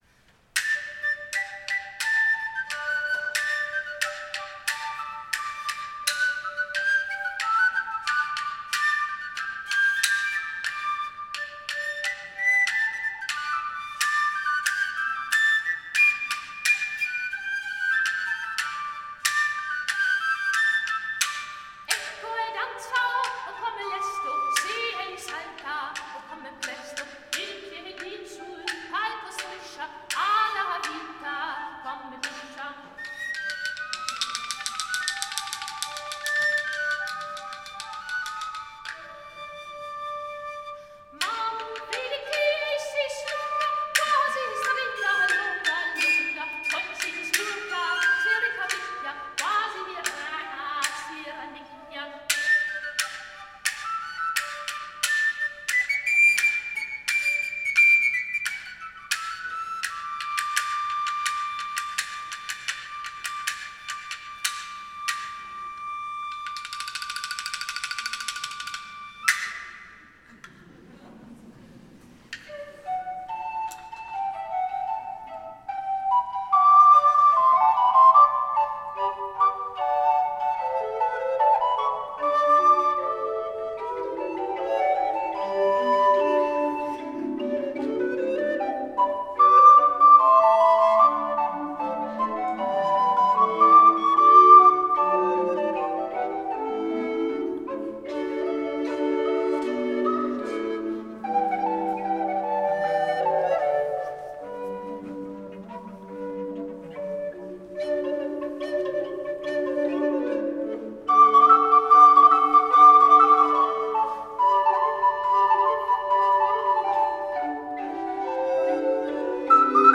Cöllner Compagney Musik historische Blasintrumente
Die Berliner Cöllner Compagney hat sich den vielfältigen Blasinstrumenten der Renaissance und ihrem enormen Klangfarbenreichtum verschrieben. Von den sanften Gemshörnern über die Blockflöten vom kleinen Sopran bis zum 2 Meter langen Subbass b is zu volltönenden Krummhörnern und Dudelsack steht ihnen ein weites Klangspektrum zur Verfügung.